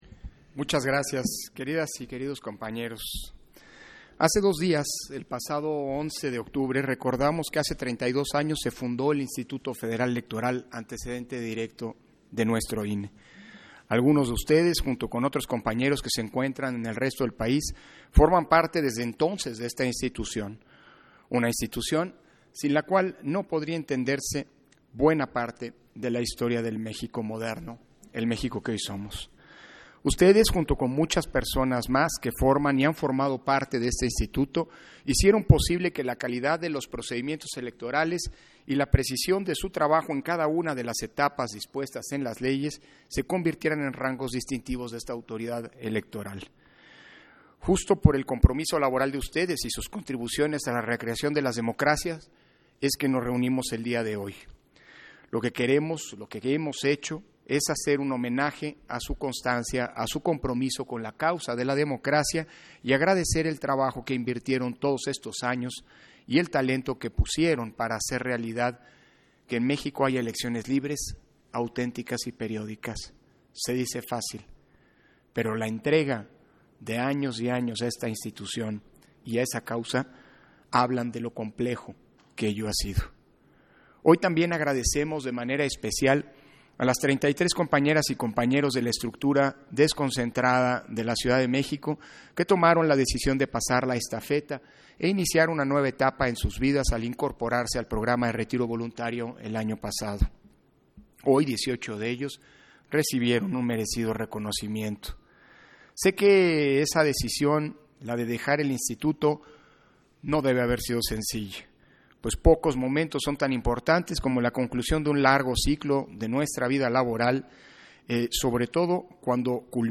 Intervención de Lorenzo Córdova, en la ceremonia de entrega de reconocimientos y medallas al personal incorporado al Programa de Retiro 2021